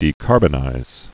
(dē-kärbə-nīz)